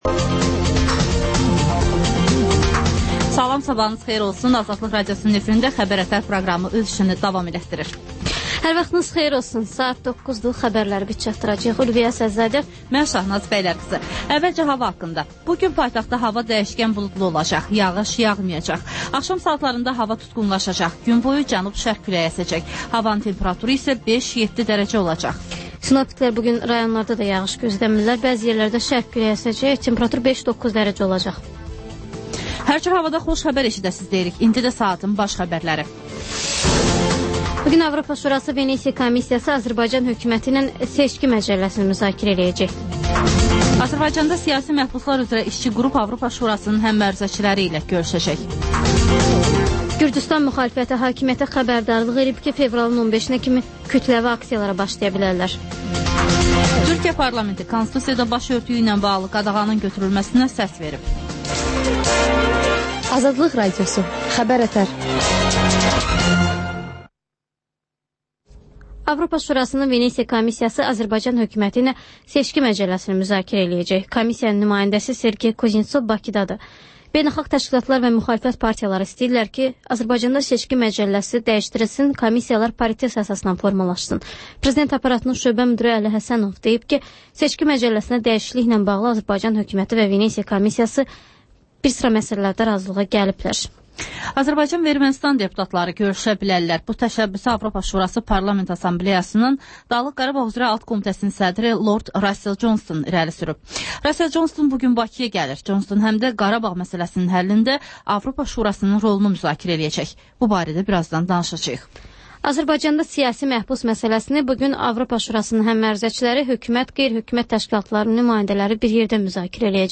Xəbər-ətər: xəbərlər, müsahibələr, daha sonra ŞƏFFAFLIQ: Korrupsiya haqqında xüsusi veriliş.